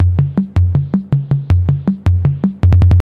Percussion Loop (Au79).wav